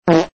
Dry Fart Sound Button: Unblocked Meme Soundboard
Play the iconic Dry Fart Sound Button for your meme soundboard!